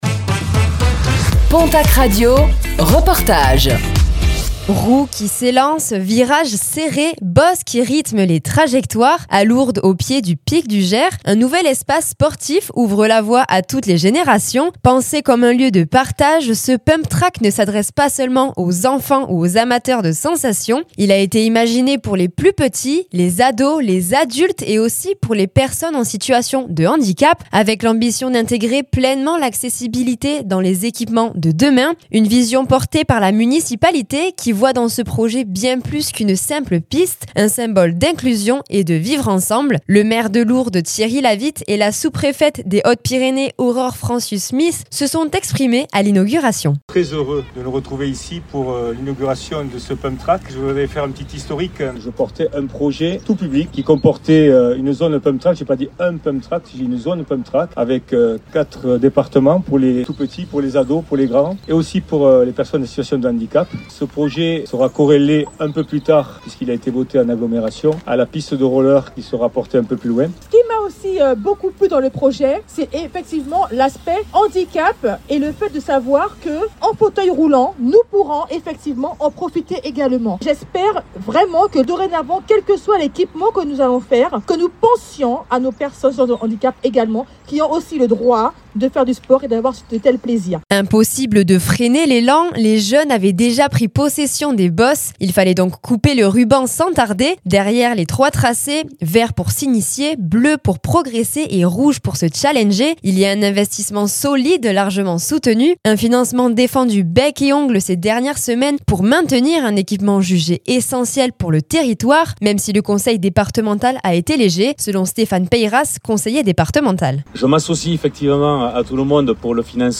Lourdes inaugure son pump track inclusif au pied du Pic du Jer - Reportage du jeudi 19 février 2026 - PONTACQ RADIO